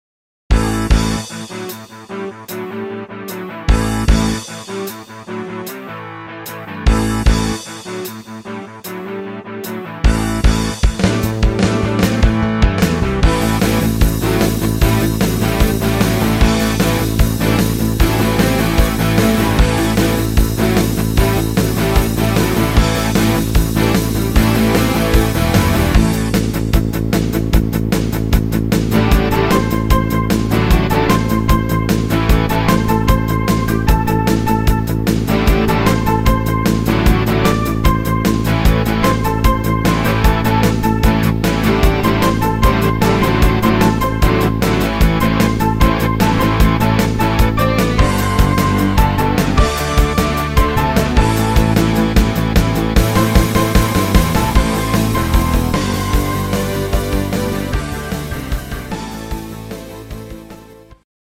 fetziger Rocksong